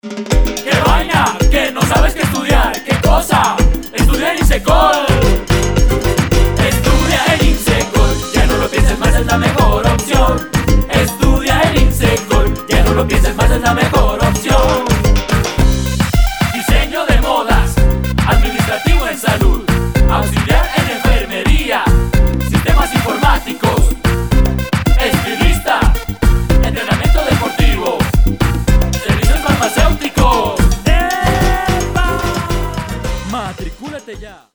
Comerciales y Jingles para Radio
Servicio: Jingle y Marca Auditiva Uso: Emisoras locales